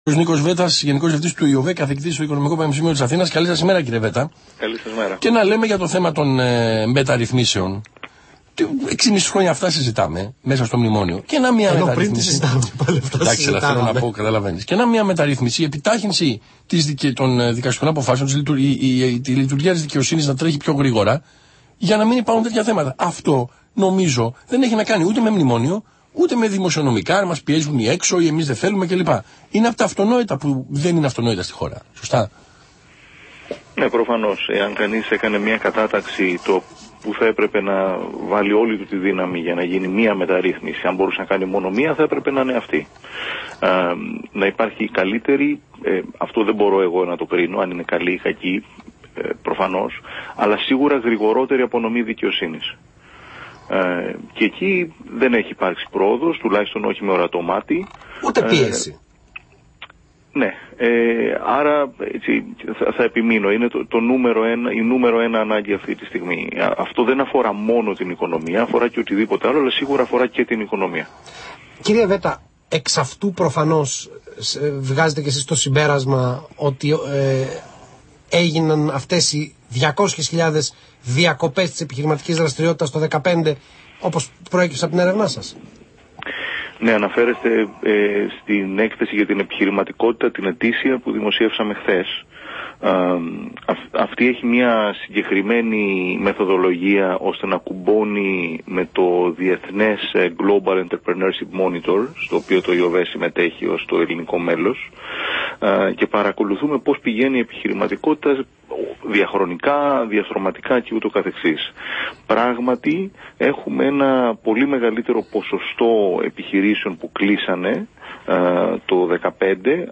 Συνέντευξη στον Αθήνα 9,84
στη ραδιοφωνική εκπομπή Η Τρόικα του Αθήνα 9,84 με αναφορά στην επιχειρηματικότητα και την ελληνική οικονομία